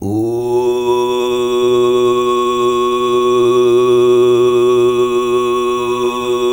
TUV2 DRONE07.wav